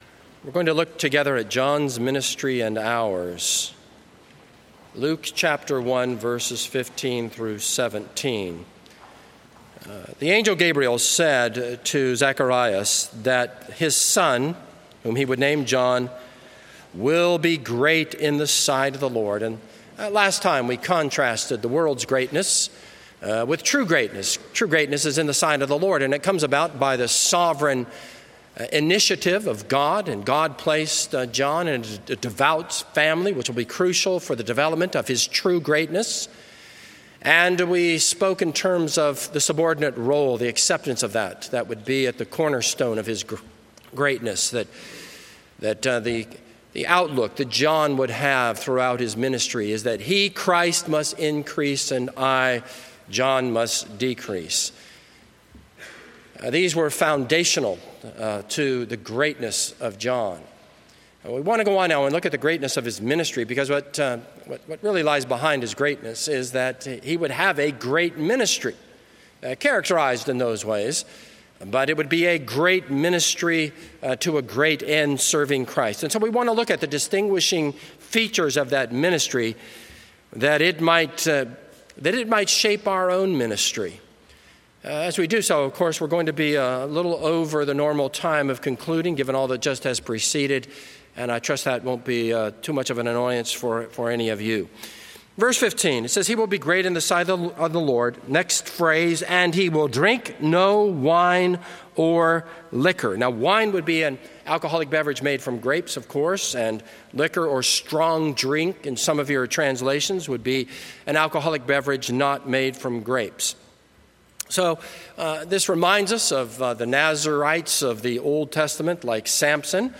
This is a sermon on Luke 1:15-17.